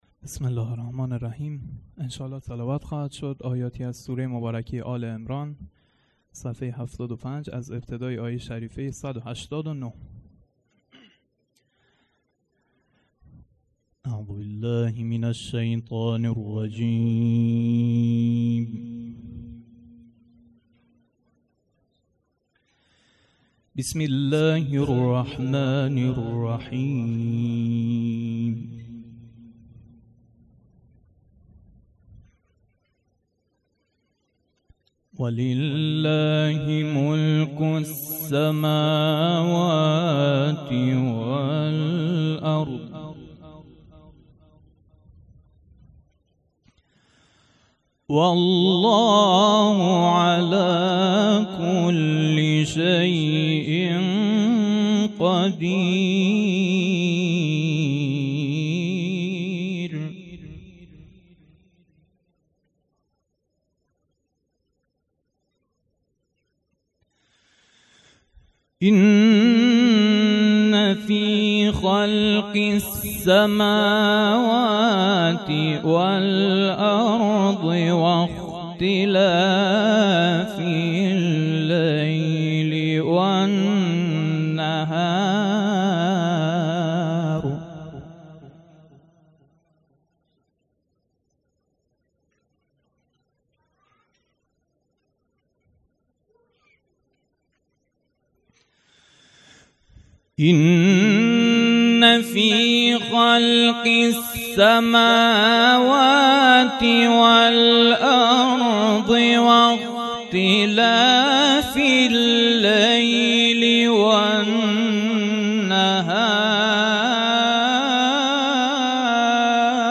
قرائت قرآن
مراسم عزاداری محرم ۱۴۰۲